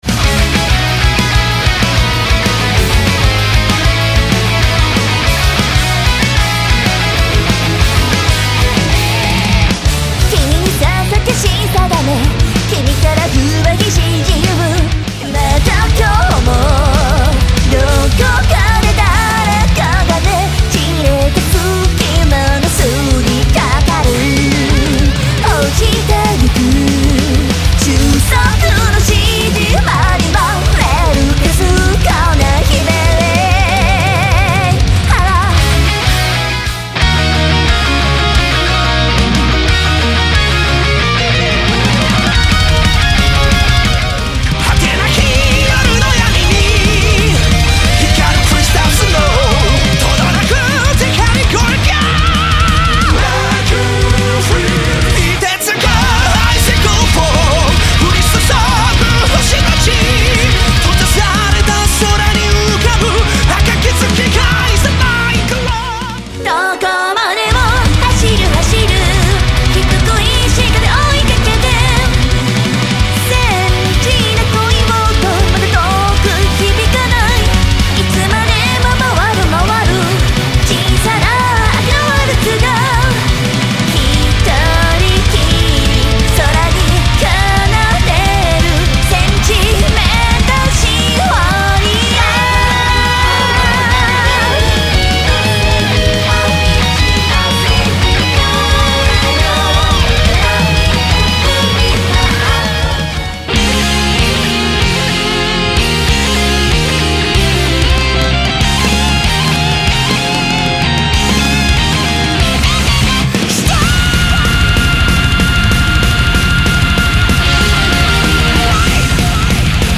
All tracks remastered, including 6 new recordings.